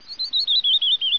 canwren.wav